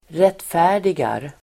Uttal: [²r'et:fä:r_digar]